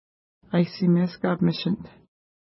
Pronunciation: a:jssi:mes ka:pməʃnt
Pronunciation